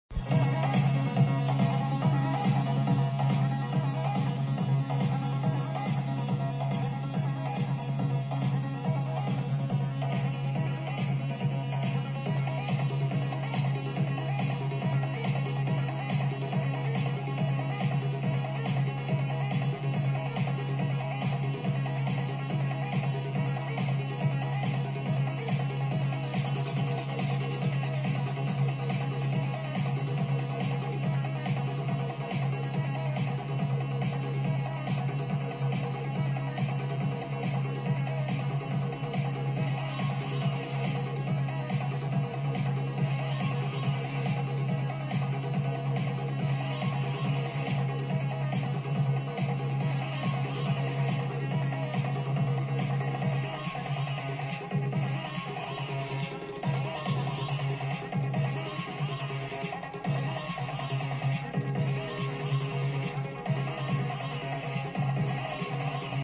Two unknown trance/electronic songs
The first one is taken from a movie from 2004, so at least it has to be that old.